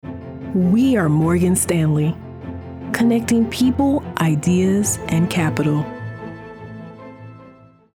Female
Yng Adult (18-29), Adult (30-50)
My voice is warm, confident, friendly, and versatile, adapting to the needs of each project.
Radio Commercials
Words that describe my voice are Friendly, Relatable, Casual.